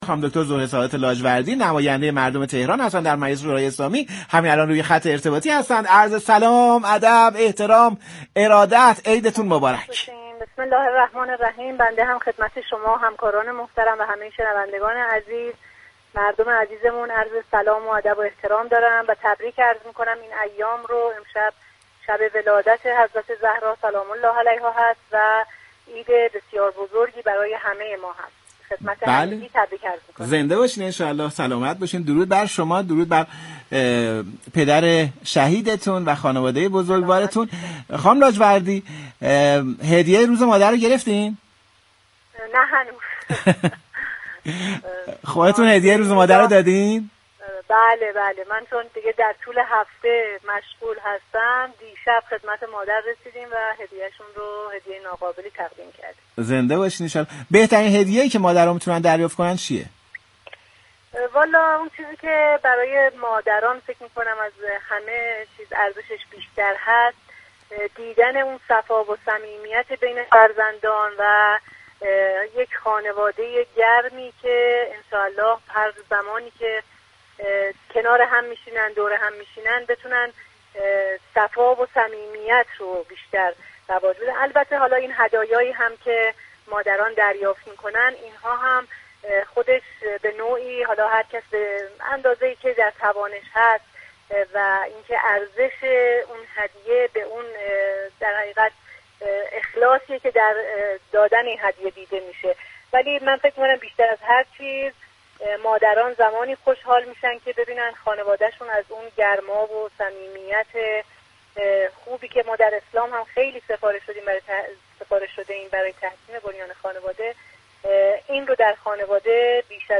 به گزارش پایگاه اطلاع رسانی رادیو تهران، زهره‌سادات لاجوردی عضو كمیسیون فرهنگی مجلس شورای اسلامی در گفت و گو با برنامه «قنات كوثر» ویژه ولادت حضرت فاطمه زهرا (س) و روز مادر اظهار داشت: مادران زمانی خوشحال می‌شوند كه شاهد گرما و صمیمت بین اعضای خانواده باشند.